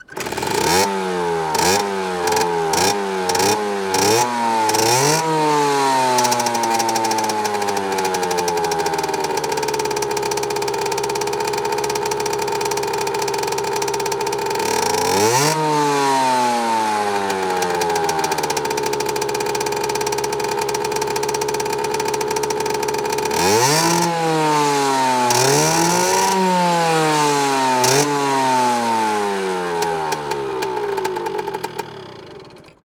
SND_moped.ogg